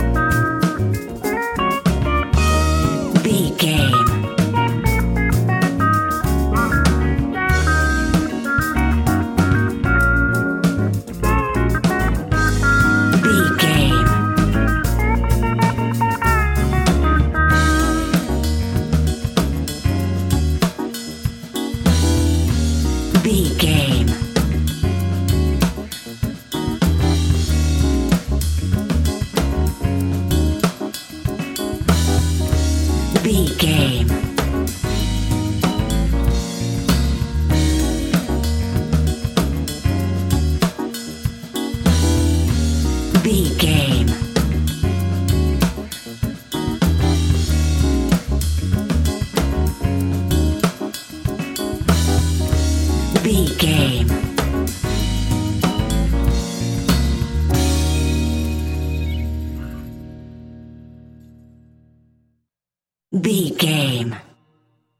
Fast paced
In-crescendo
Uplifting
Ionian/Major
D♯